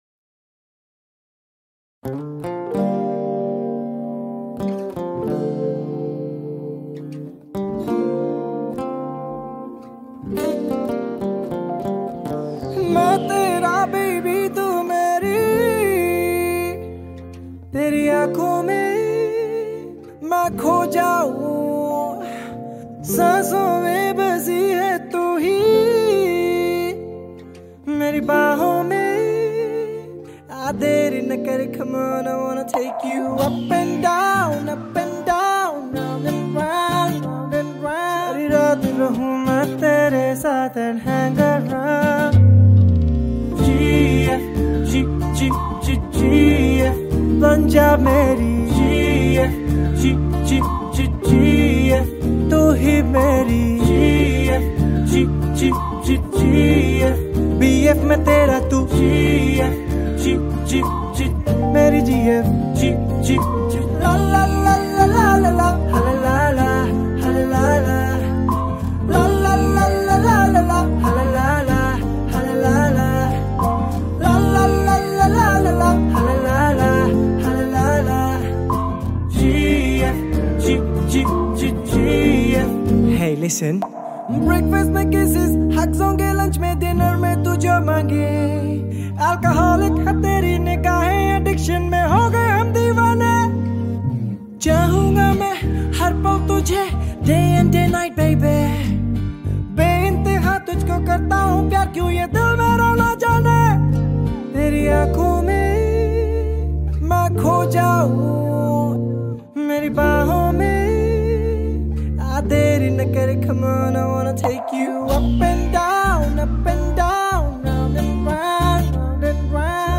Pop Songs
Indian Pop